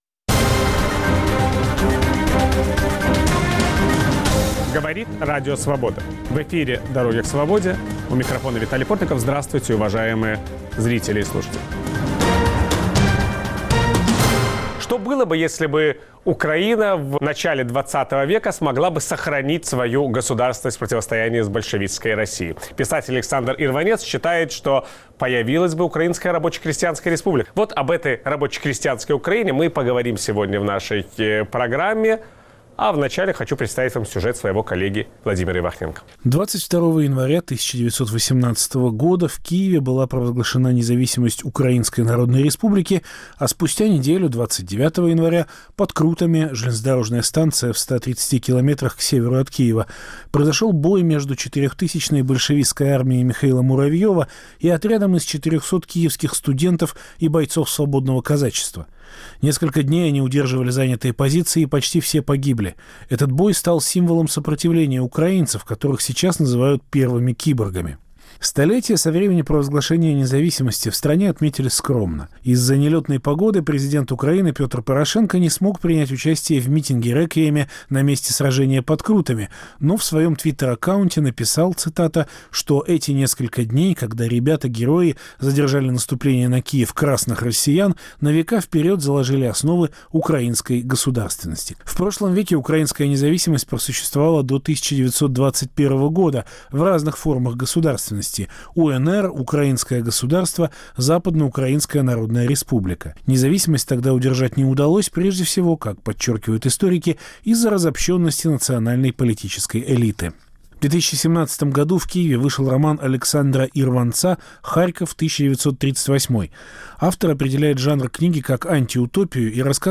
В 100-летнюю годовщину провозглашения независимости Украины гость эфира, писатель Александр Ирванец задумался над тем, что произошло бы, если бы та, «первая» Украина выстояла бы. Виталий Портников беседует с Александром Ирванцом о его новом романе «Харьков 1938».